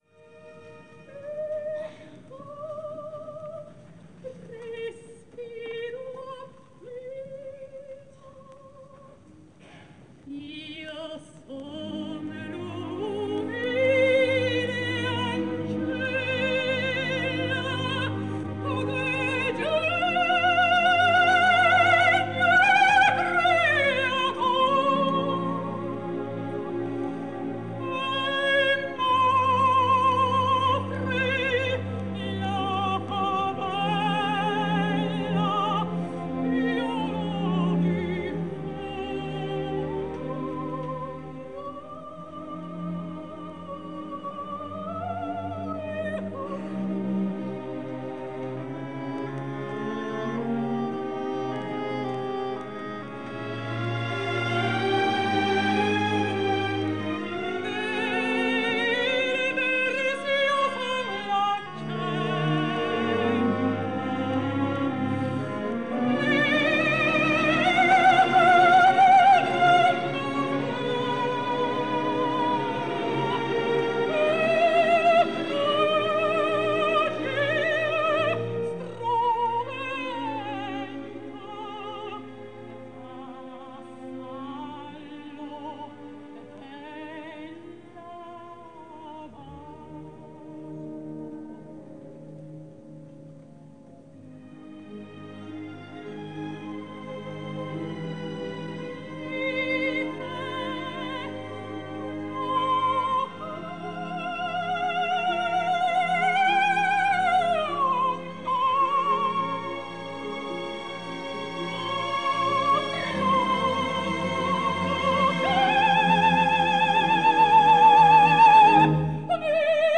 Жанр: Opera
итальянская оперная певица, сопрано.